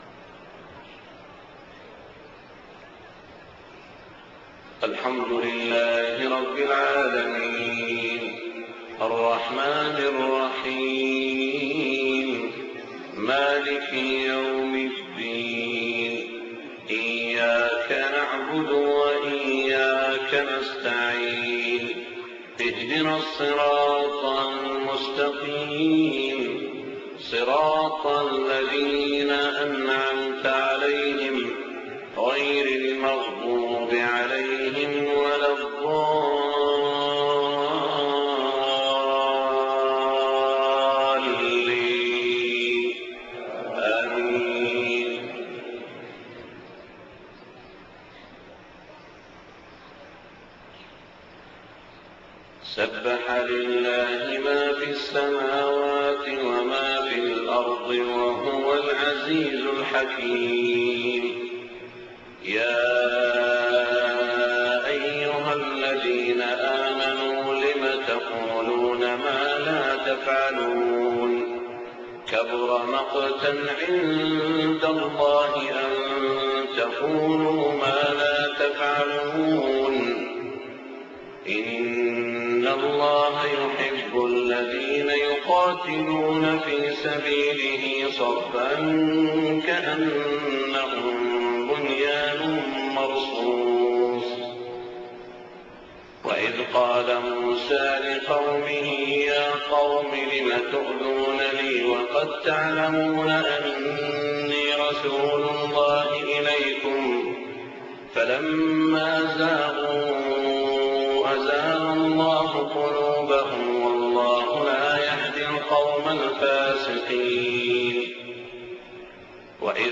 صلاة الفجر 1-9-1426 سورة الصف > 1426 🕋 > الفروض - تلاوات الحرمين